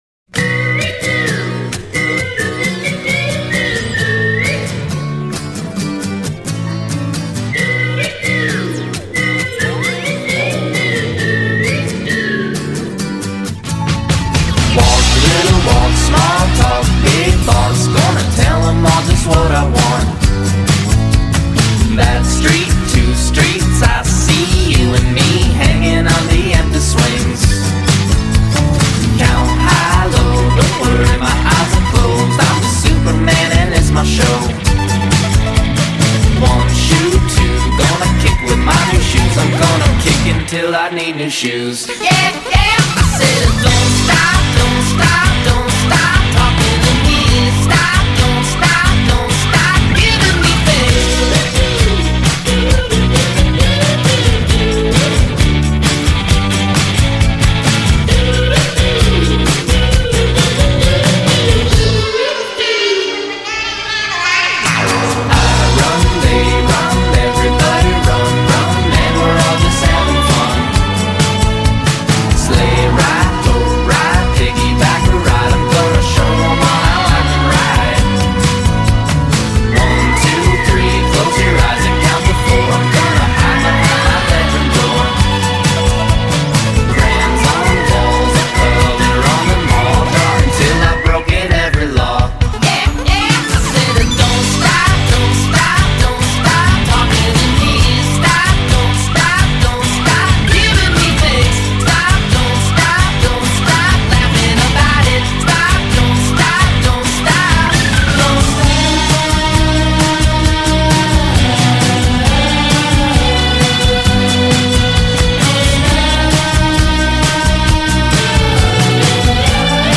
Very dancey, summery and fun.
Such a fun song.